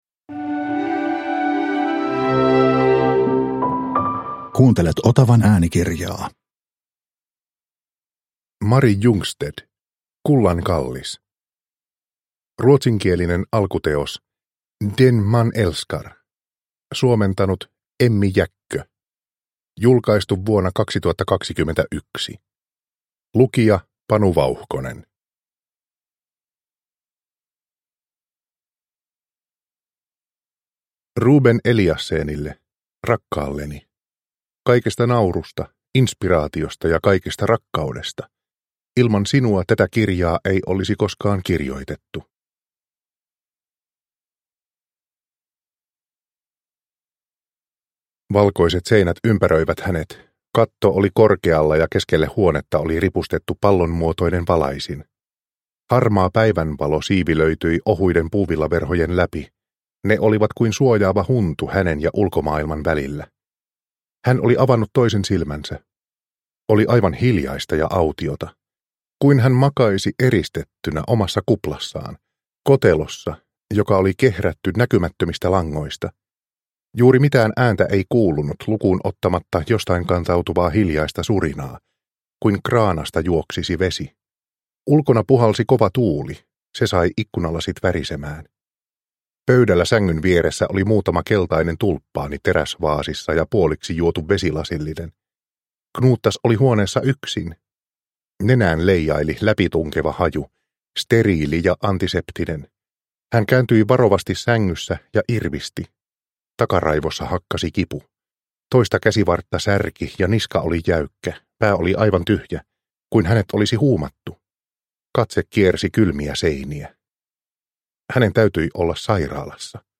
Kullan kallis – Ljudbok – Laddas ner